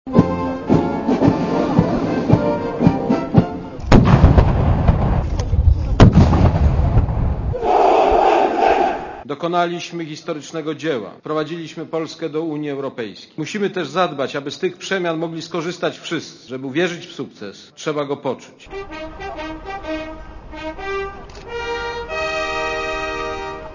Uroczysta odprawa wart - jak co roku 3 maja - odbyła się przed Grobem Nieznanego Żołnierza na Placu Piłsudskiego w Warszawie.
Komentarz audio